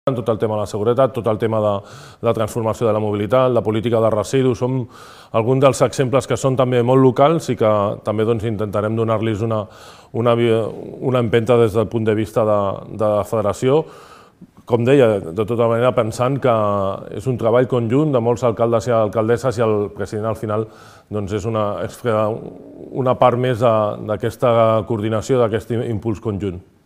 En declaracions a La Xarxa, David Bote considera que el món local té sobre la taula fer front al repte de la seguretat.